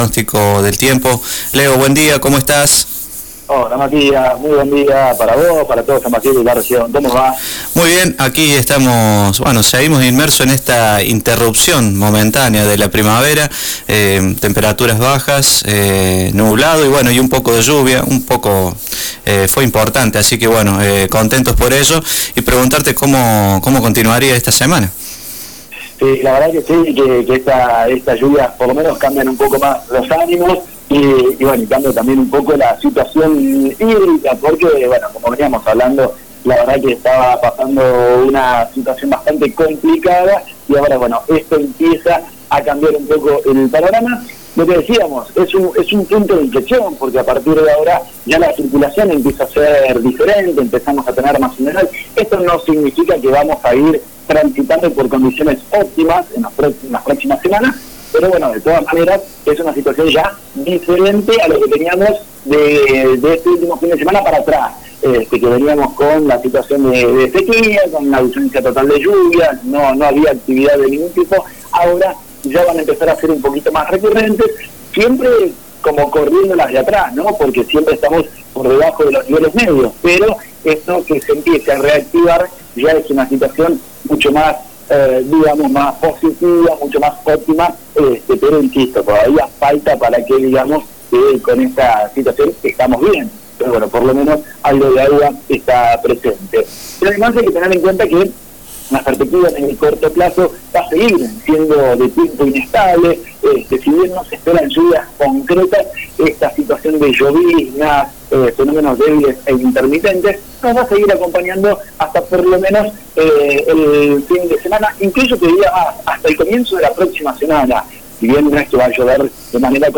pronóstico del tiempo